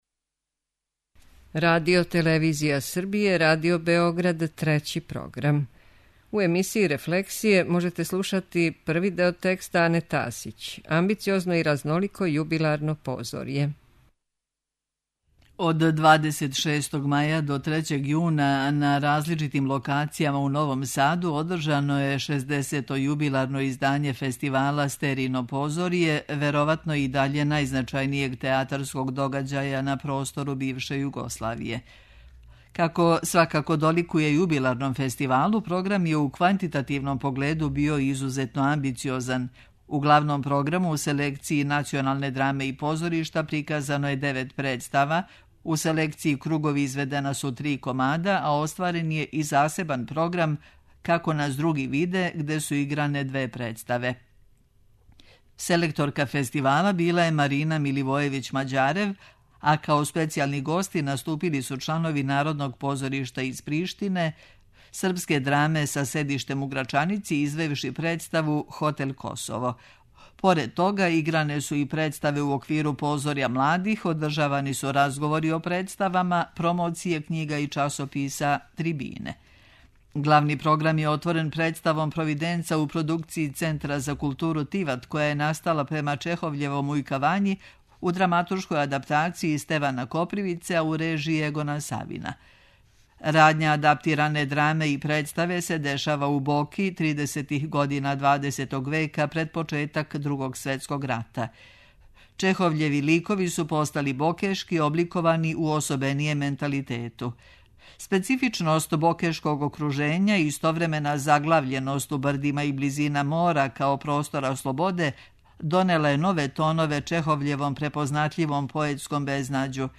преузми : 13.67 MB Рефлексије Autor: Уредници Трећег програма У емисијама РЕФЛЕКСИЈЕ читамо есеје или научне чланке домаћих и страних аутора.